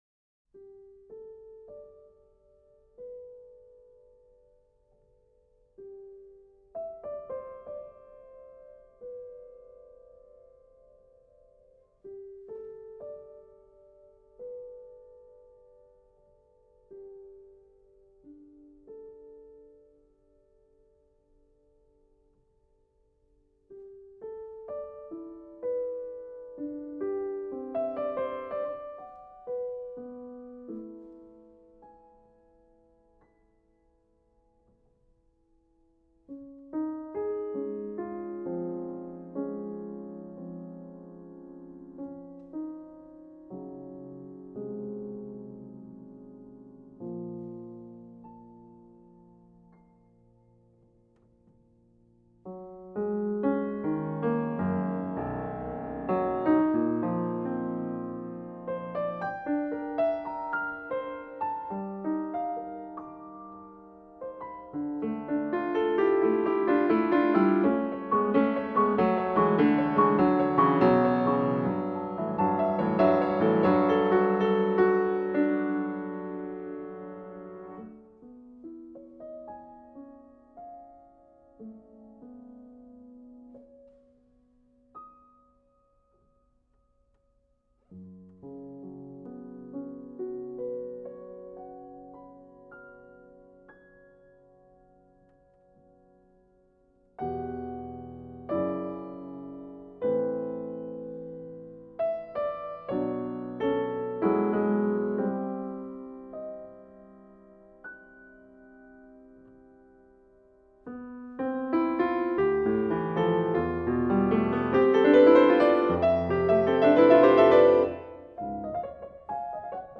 for Piano (1980)
piano.